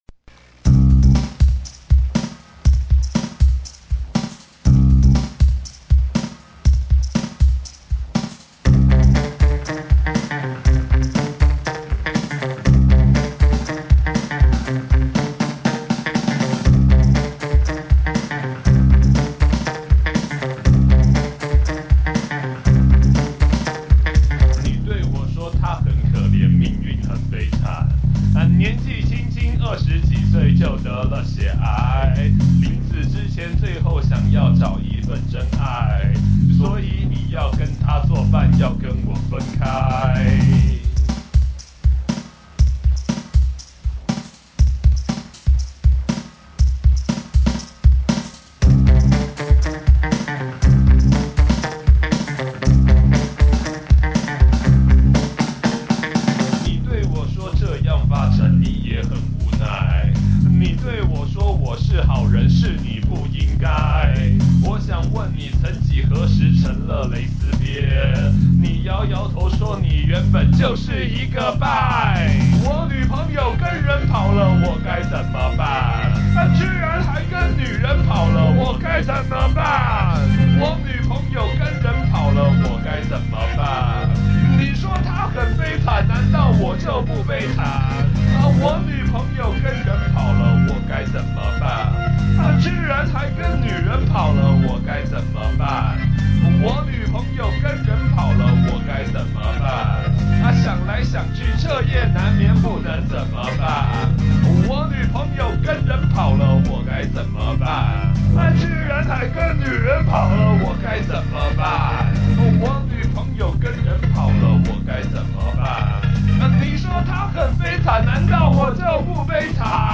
第三件事情，GarageBand這套簡易作曲軟體真的不錯玩，算是個有趣的玩具，在聲音庫裡頭，現成可用的素材很多，就算自己沒有輸入裝置，也可以用現成的素材，快速組合出一首曲子來。